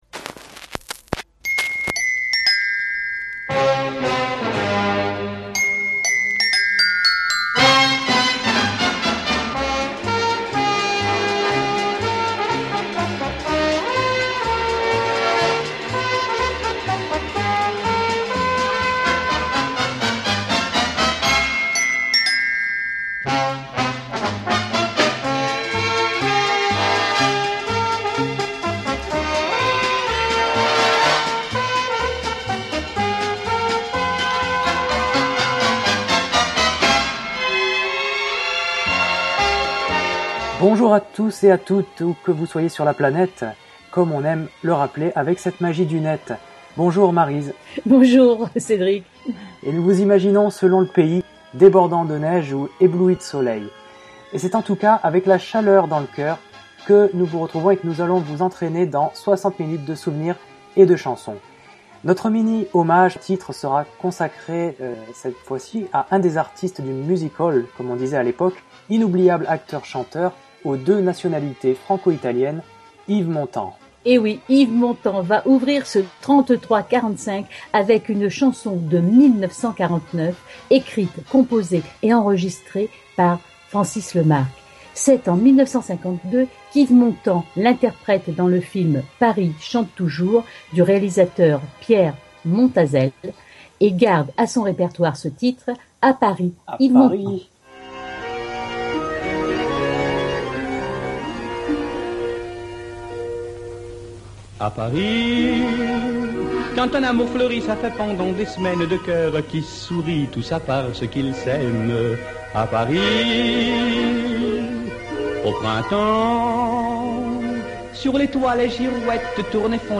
Le Podcast Journal, en partenariat avec Radio Fil, vous propose cette émission musicale dédiée aux années vinyles